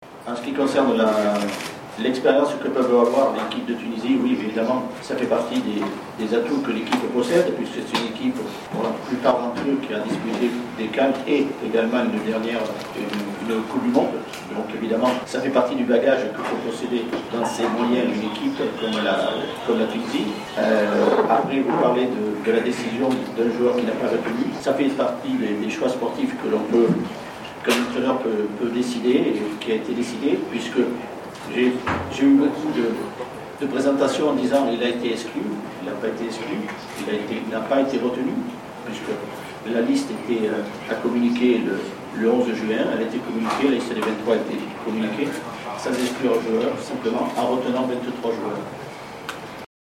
عقد مدرب المنتخب الوطني التونسي آلان جيريس ندوة صحفية بملعب السويس صحبة اللاعب وجدي كشريدة للحديث حول مواجهة أنغولا يوم الإثنين 24 جوان 2019 بداية من الساعة السادسة مساء ضمن الجولة الأولى من منافسات المجموعة الخامسة لكأس الأمم الإفريقية 2019 التي تستضيفها مصر.